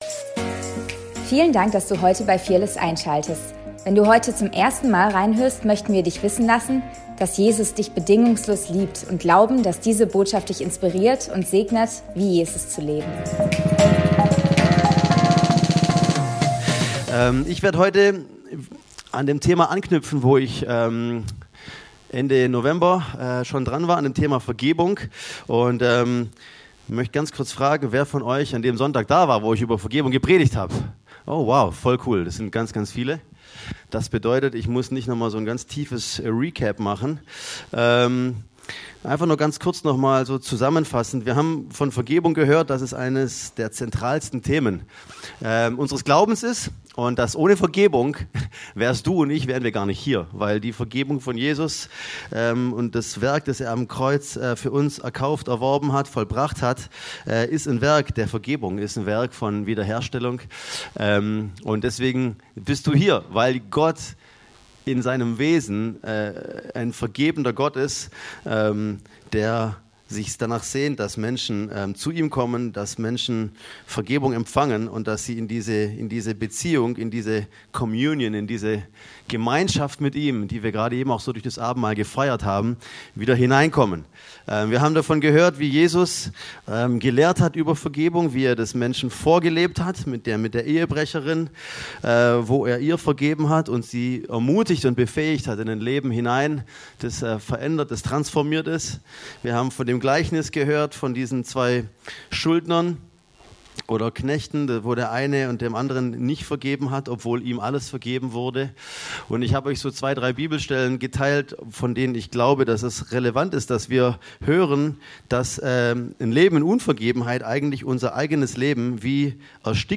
Predigt vom 07.12.2025